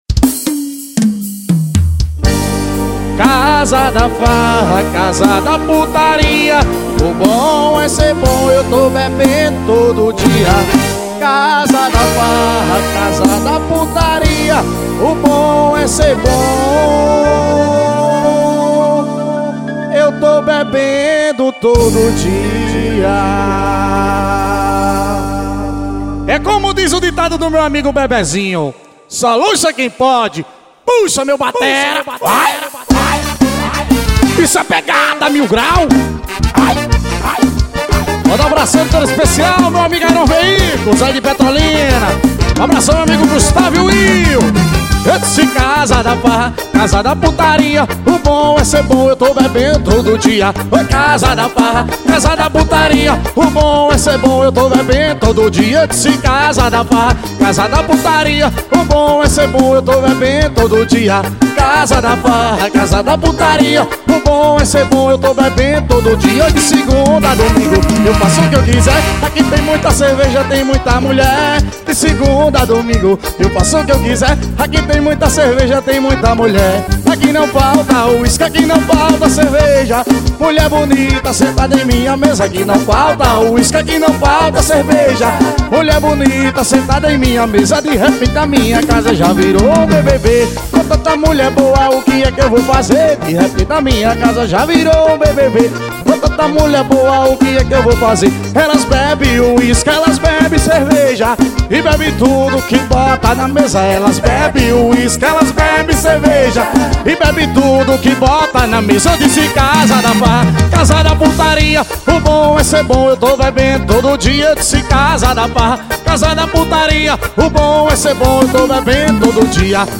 Ao Vivo na Bahia